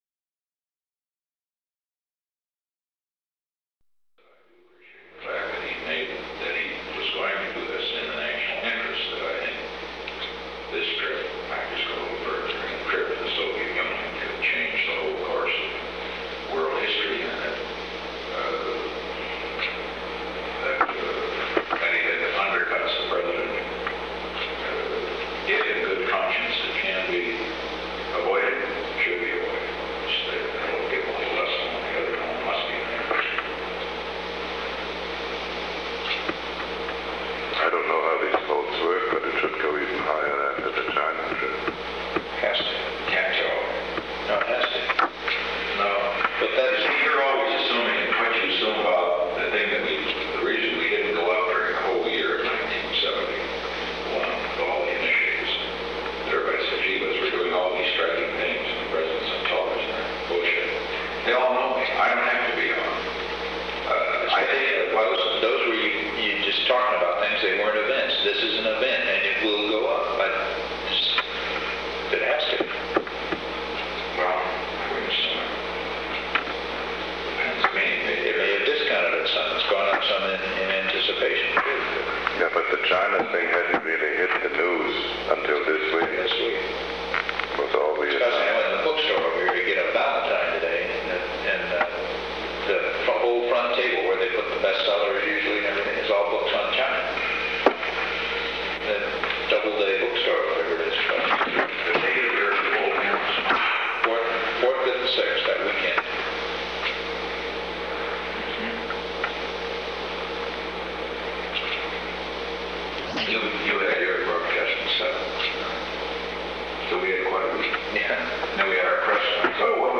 The Oval Office taping system captured this recording, which is known as Conversation 671-004 of the White House Tapes.